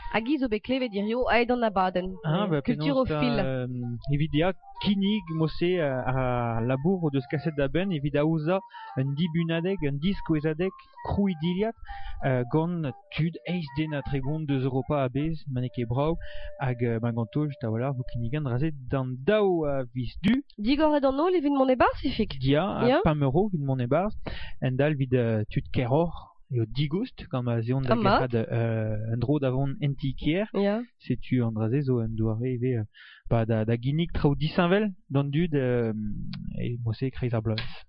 14 October 2012 at 6:41 pm The music in the background is a bit of a giveaway – unmistakeably Breton. Also, I recognise a few words (I think) from Welsh, yet the pronunciation, intonation and rhythm sound French.
The recording comes from Radio Breizh .